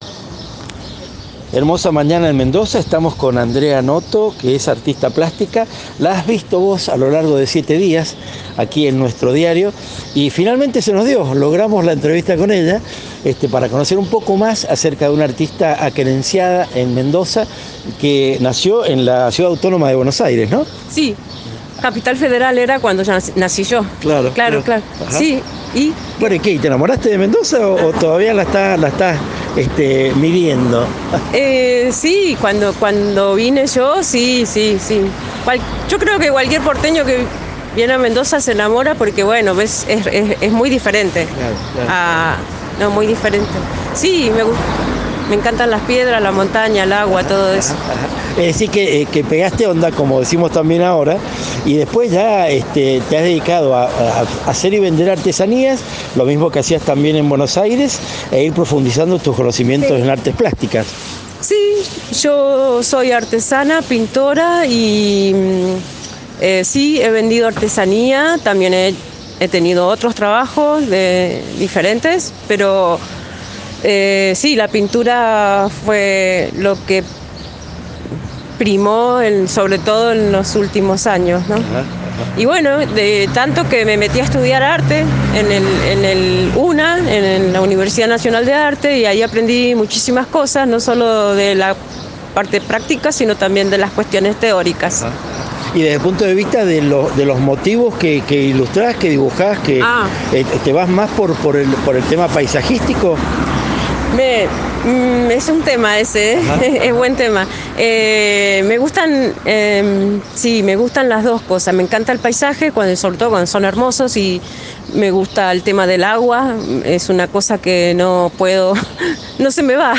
Rehuye de flashes y parafernalia tecnológica, pero habilita la charla que podrás escuchar grabada, líneas abajo.